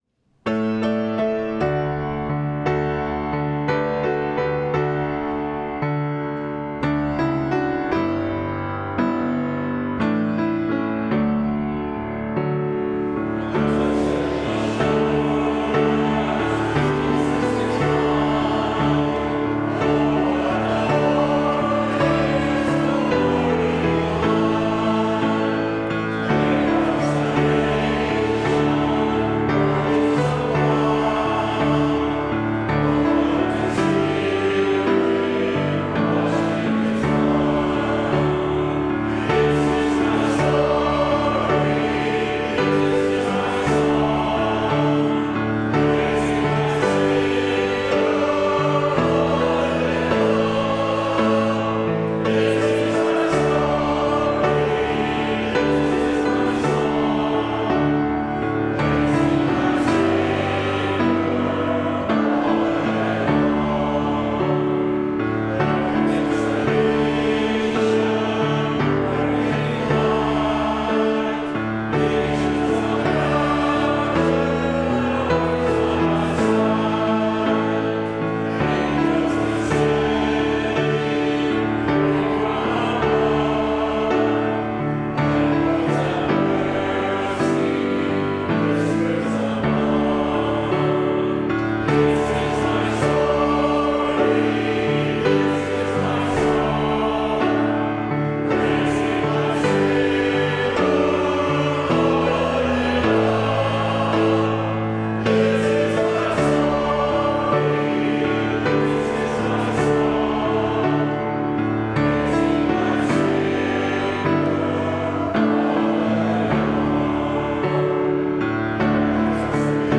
Service of Worship
Closing Hymn — #369 “Blessed Assurance”